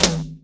TOM     1B.wav